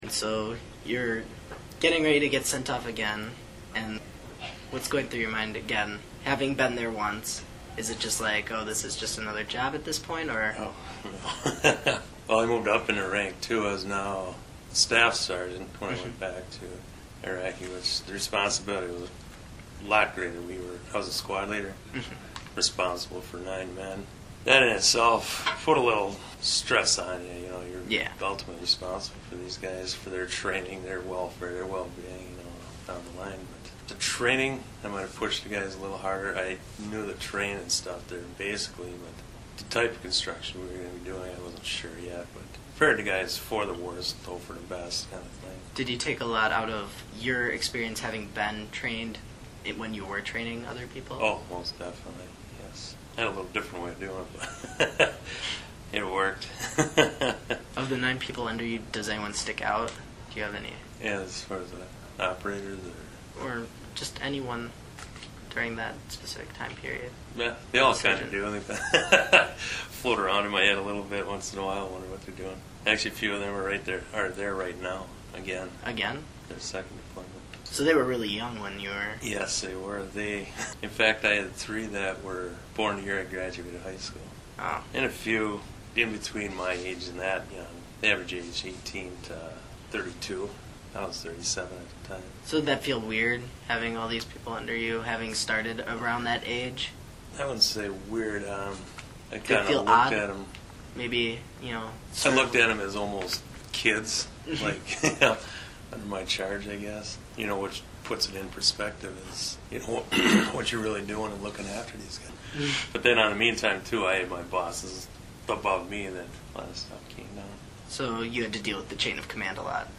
The following are excerpts from local Iraq War Veterans collected as part of the Slinger Area History Culture Project.